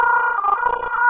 周边环境 " 周边气氛04
描述：一段声音与奇怪流动的粒状声音卷曲在一起。
标签： 环境 阴森恐怖 大气 黑色
声道立体声